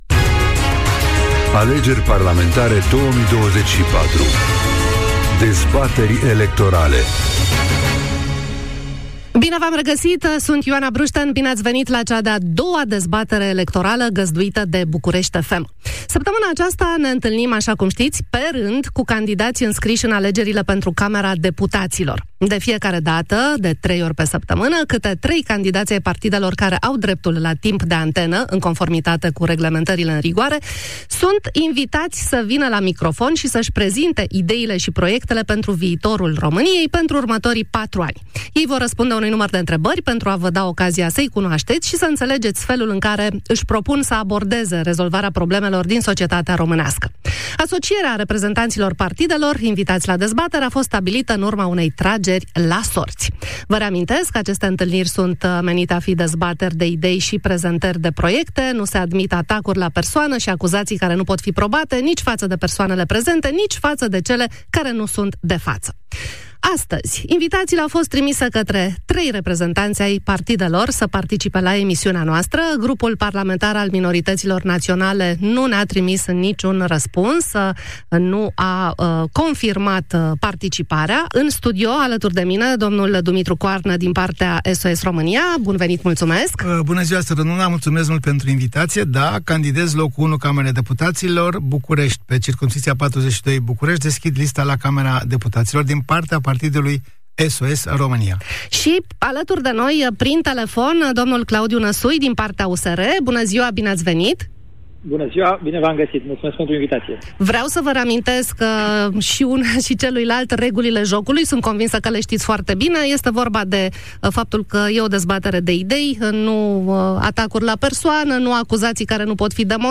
Dezbatere electorală parlamentară > Dumitru Coarnă și Claudiu Năsui | AUDIO